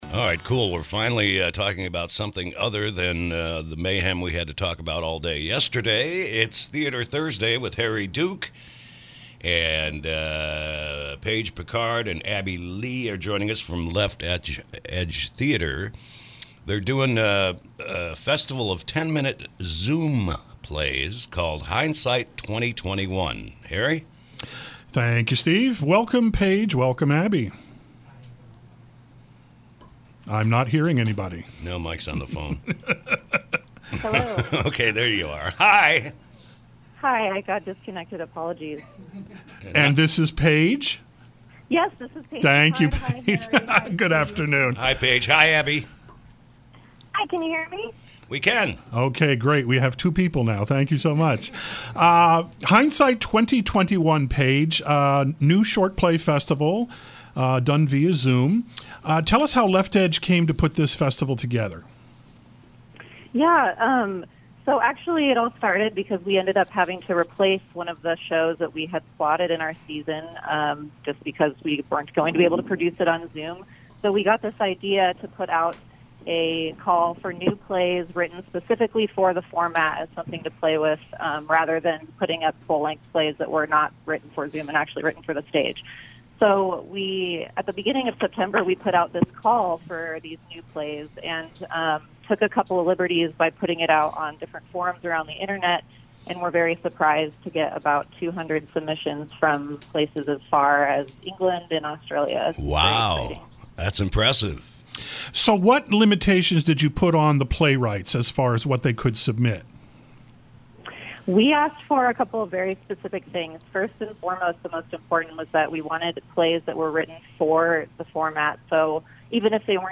KSRO Interview – “Hindsight 2021”